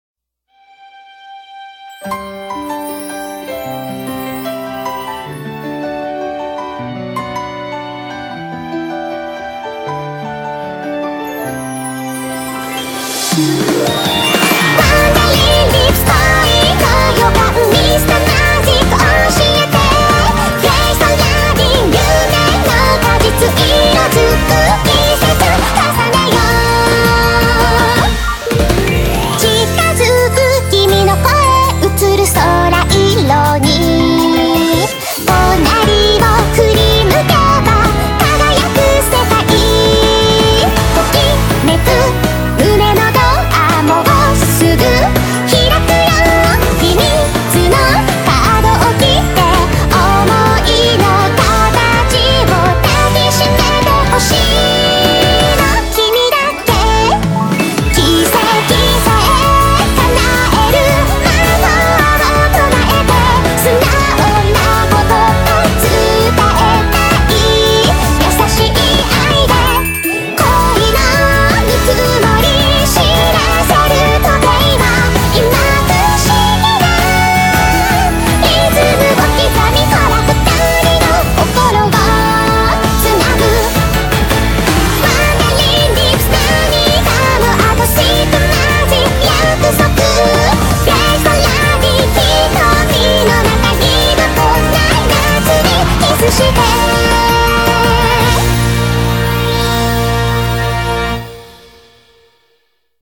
BPM65-164
Audio QualityCut From Video